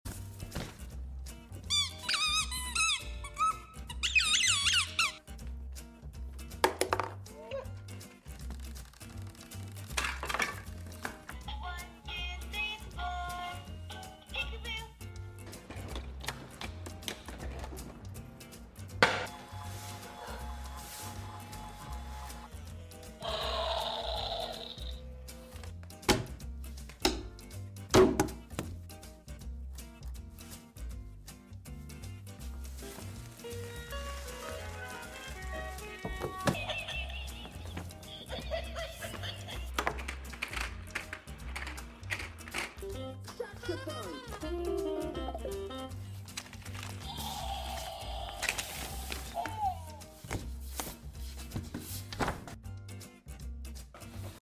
Animalia's Orangutan Freddie playing ASMR